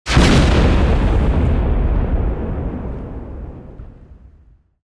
explosion3.ogg